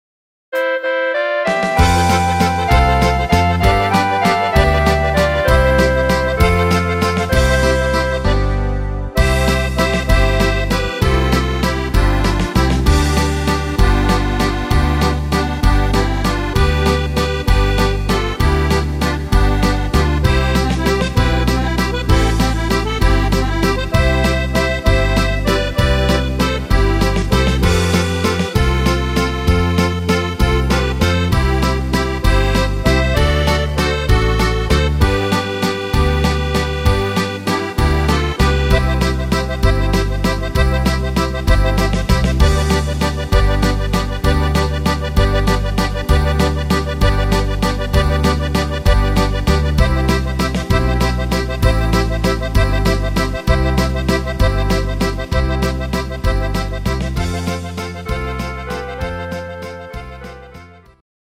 Rhythmus  Waltz
Art  Volkstümlich, Deutsch